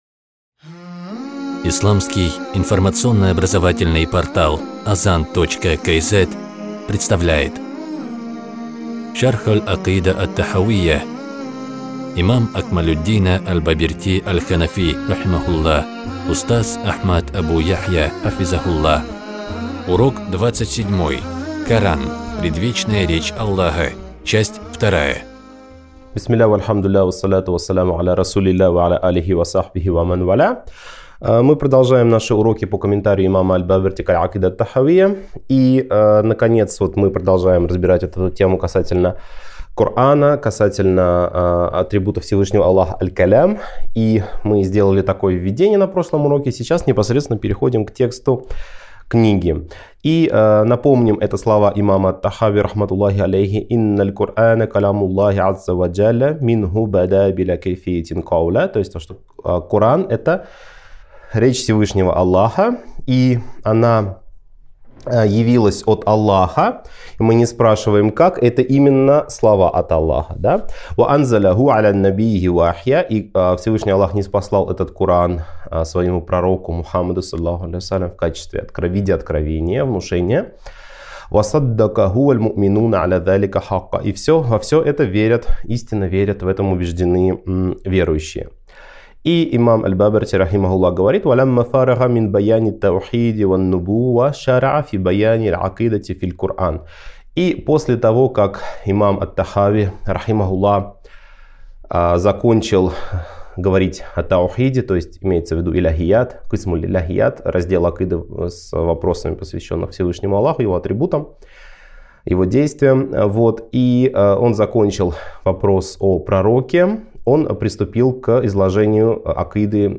Цикл уроков по акыде второго уровня сложности, рассчитанный на слушателя, освоившего основы акыды. В комментарии имама аль-Бабирти положения акыды разбираются более углубленно, приводятся доказательства из Корана и Сунны, разбираются разногласия ашаритов и матуридитов с другими исламскими течениями.